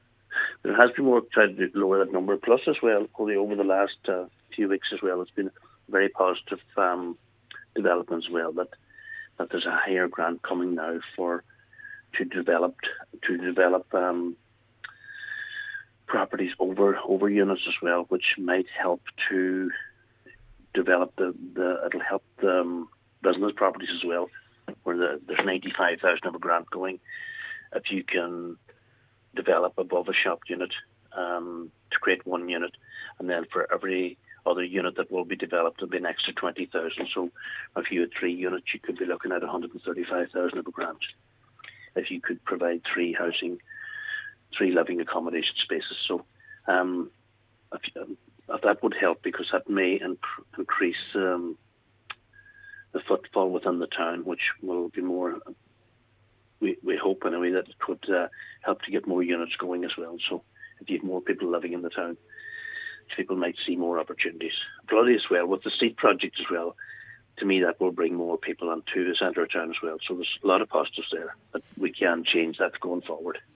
Cllr Harley says the increased funding will help inject life into the area: